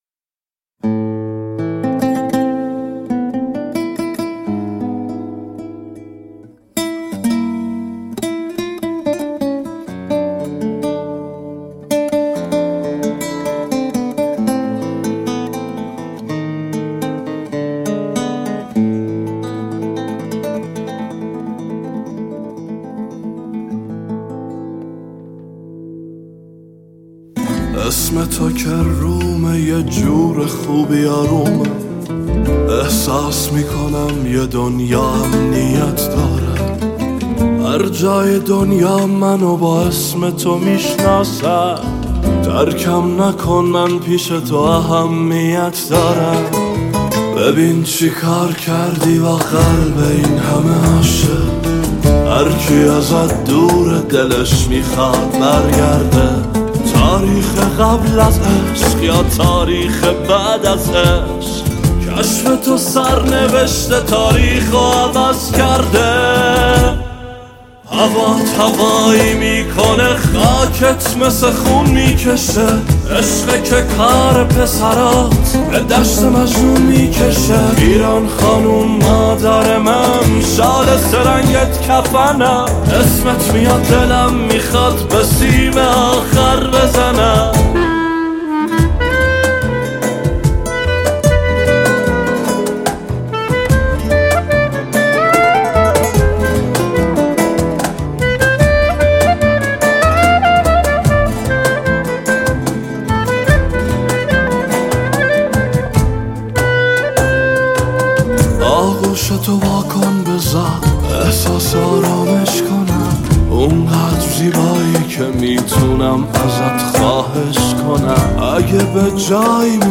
آهنگهای پاپ فارسی